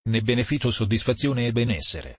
ne beneficio soddisfazione e benessere Meme Sound Effect
Category: Reactions Soundboard